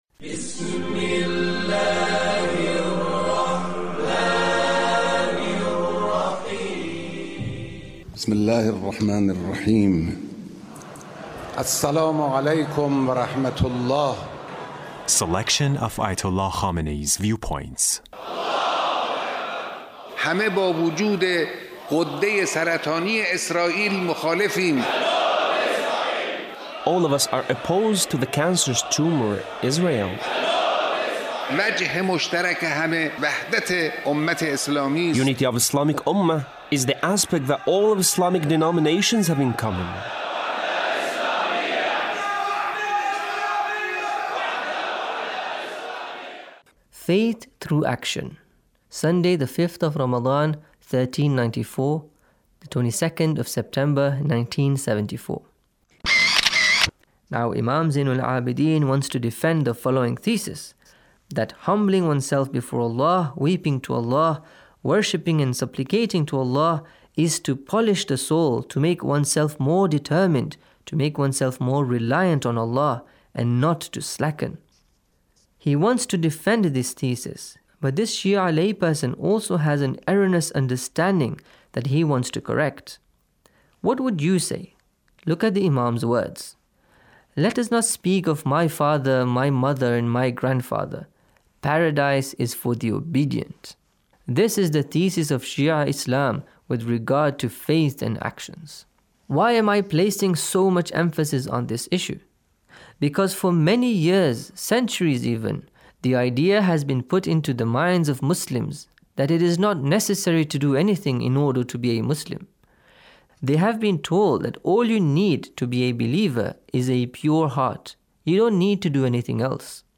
Leader's Speech on Taqwa